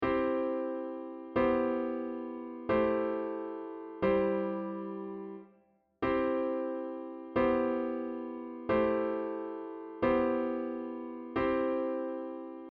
C-Bass-Cliche
C-Bass-Cliche.mp3